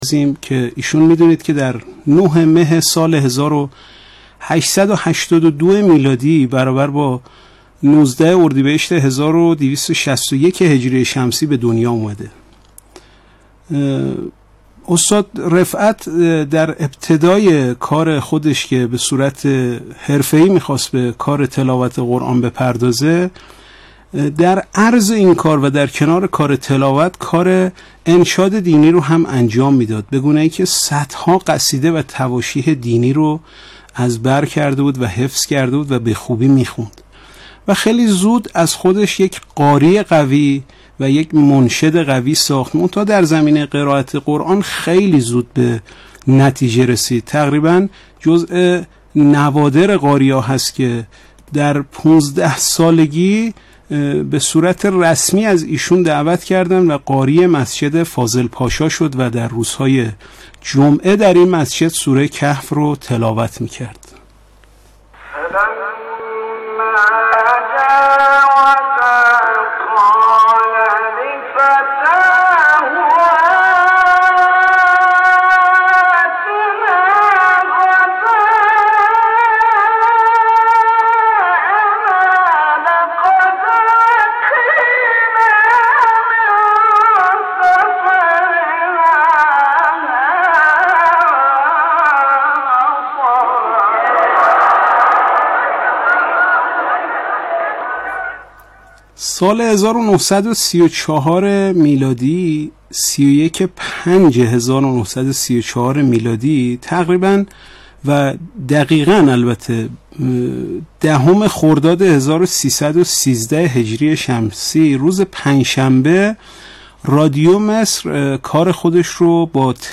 یادآور می‌شود، این تحلیل در برنامه «اکسیر» به تاریخ 1 شهریورماه ۱۳۹۷ ساعت ۱۶:۳۰ تا ۱۸ از شبکه رادیویی قرآن پخش شد.